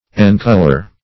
encolor - definition of encolor - synonyms, pronunciation, spelling from Free Dictionary Search Result for " encolor" : The Collaborative International Dictionary of English v.0.48: Encolor \En*col"or\, v. t. To color.